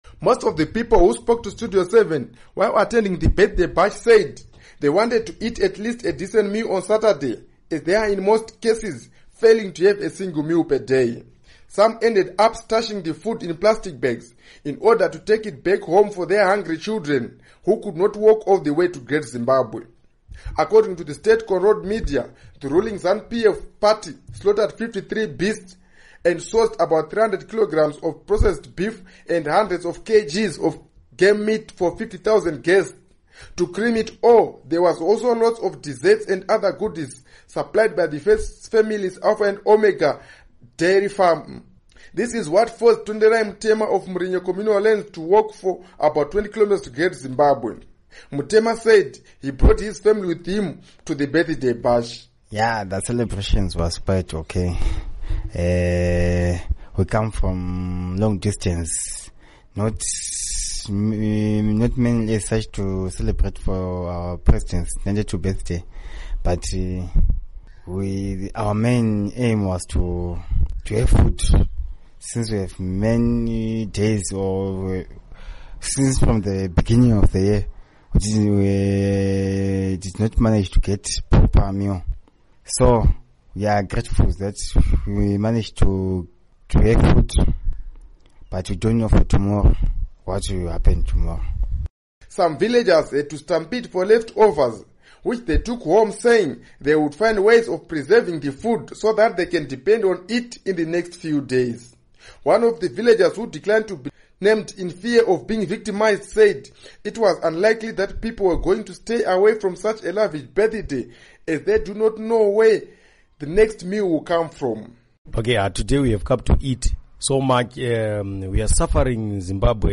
Reports on Masvingo Residents' Take on President Mugabe's Birthday Bash